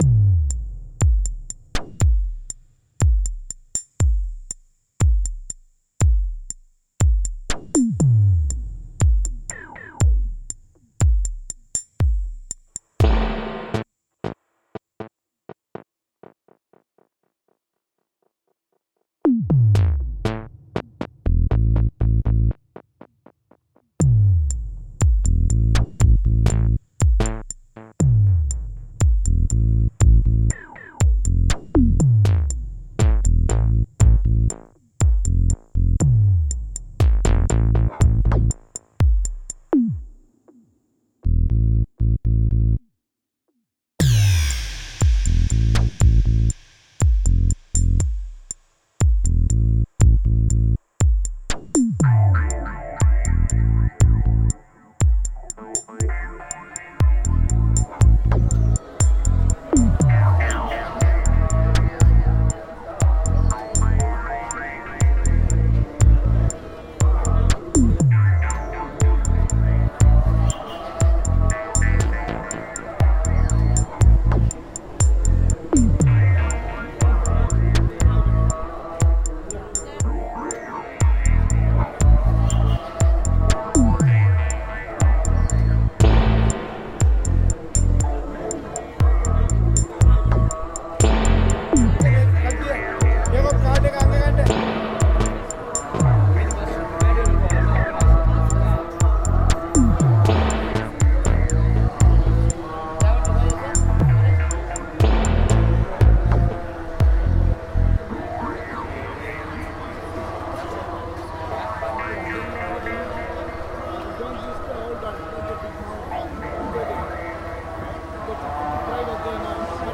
Kandy call to prayer reimagined